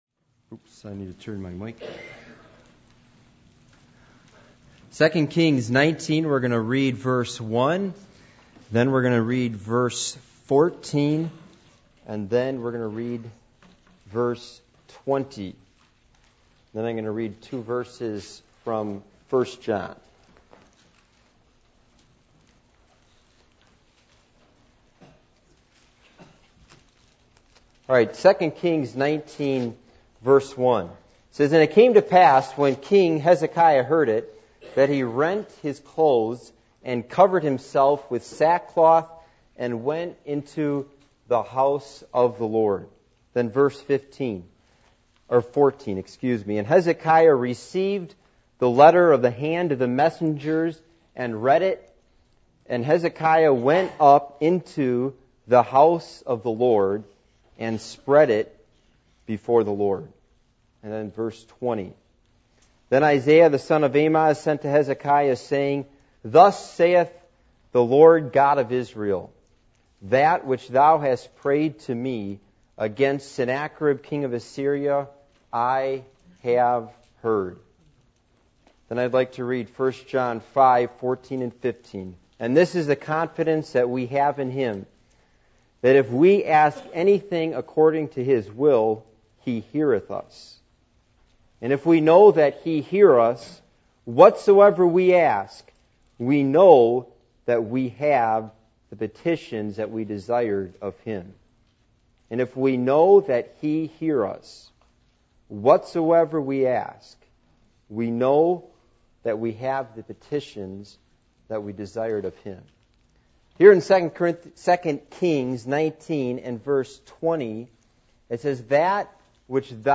Passage: 2 Kings 19:1-20 Service Type: Midweek Meeting